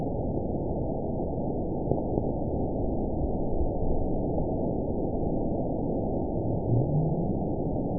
event 920013 date 02/06/24 time 01:31:56 GMT (1 year, 4 months ago) score 9.55 location TSS-AB08 detected by nrw target species NRW annotations +NRW Spectrogram: Frequency (kHz) vs. Time (s) audio not available .wav